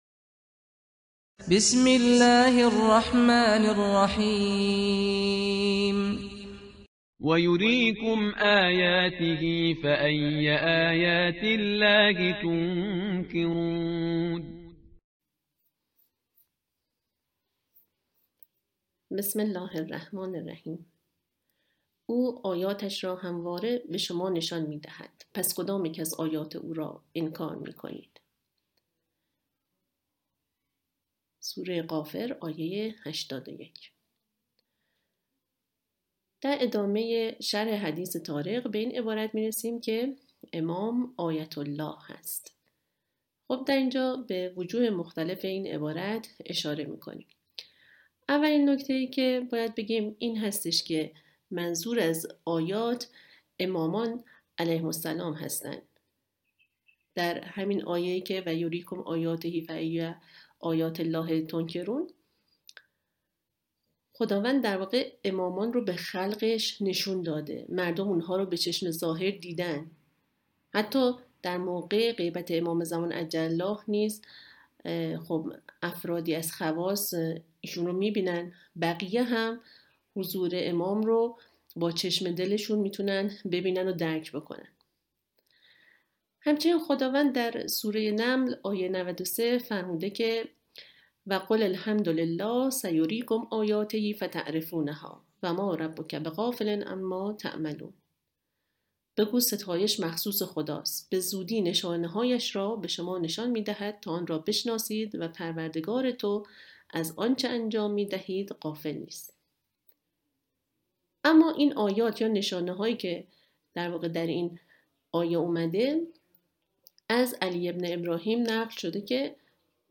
متن سخنرانی- قسمت سیزدهم: بسم الله الرحمن الرحیم وَ يُريكُمْ آياتِهِ فَأَيَّ آياتِ اللهِ تُنْكِرُونَ (او آیاتش را همواره به شما نشان می‌دهد؛ پس کدام یک از آیات او را انکار می‌کنید؟)!.